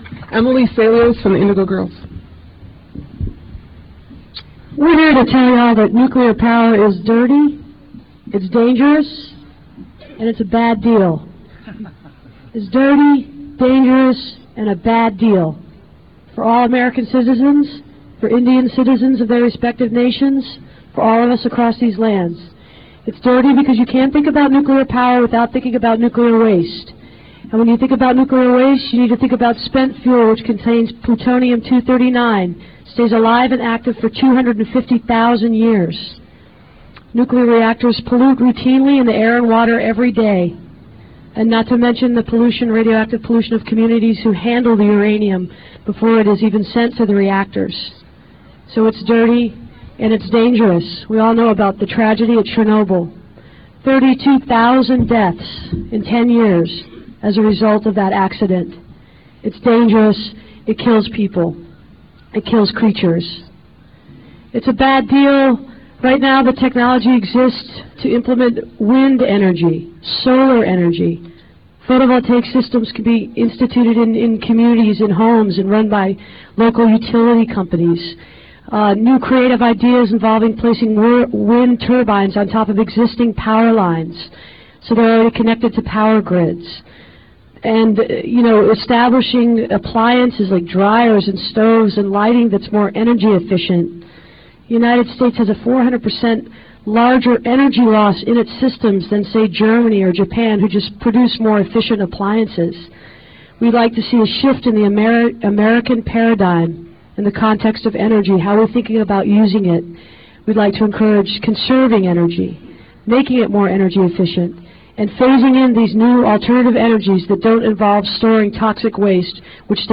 lifeblood: bootlegs: 1997-09-24: honor the earth press conference - washington, d.c.
08. press conference - emily saliers (2:31)